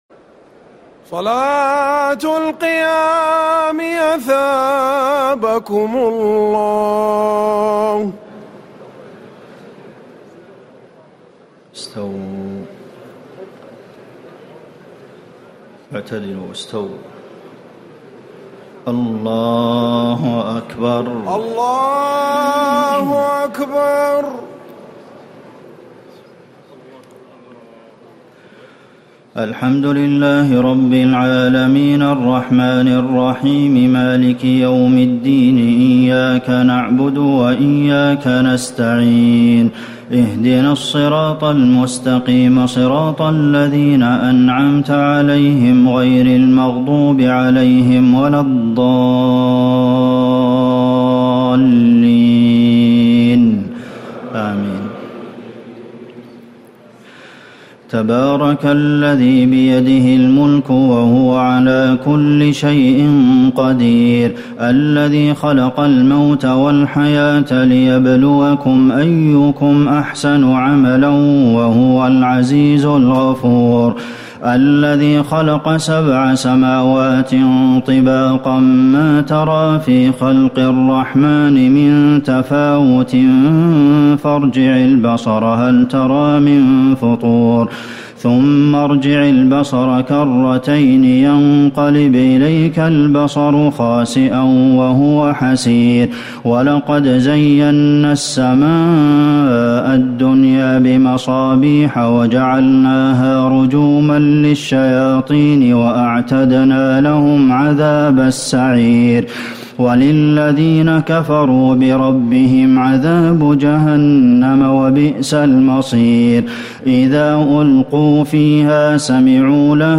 تراويح ليلة 28 رمضان 1439هـ من سورة الملك الى نوح Taraweeh 28 st night Ramadan 1439H from Surah Al-Mulk to Nooh > تراويح الحرم النبوي عام 1439 🕌 > التراويح - تلاوات الحرمين